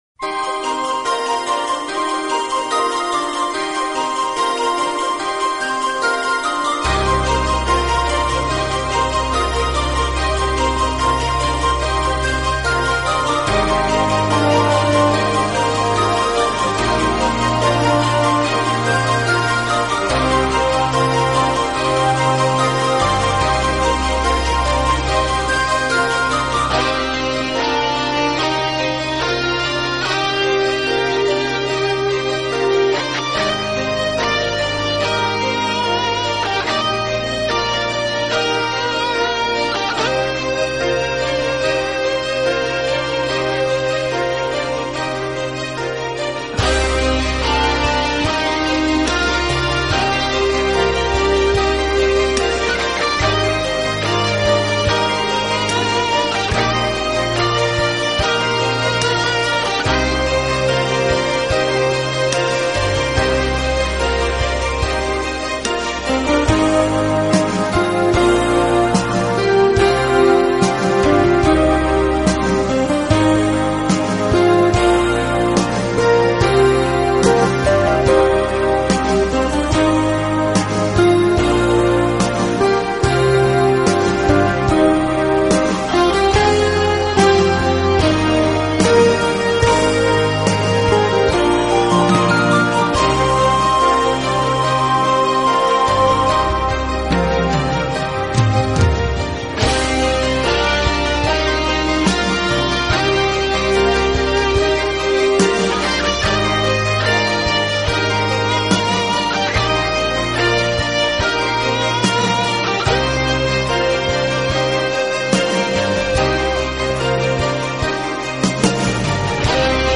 【新世纪纯音乐】